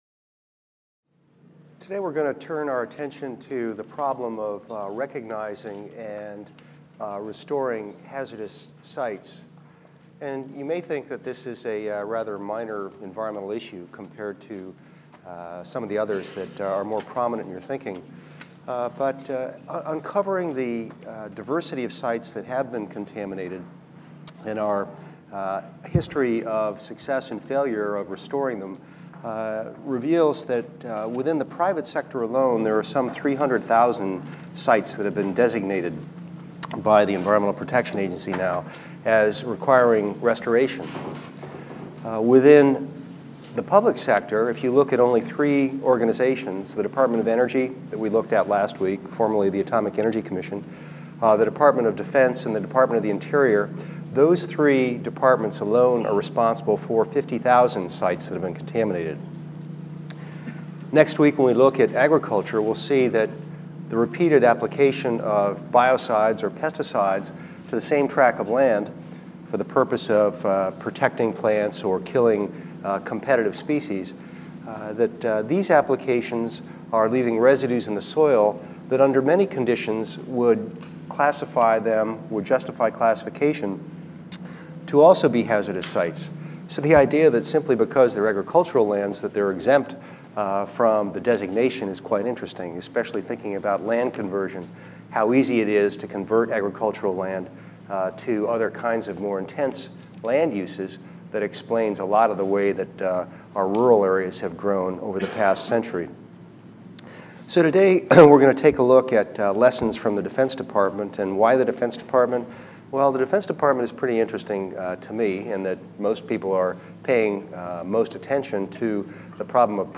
EVST 255 - Lecture 6 - Marine Food-Chains: Mercury | Open Yale Courses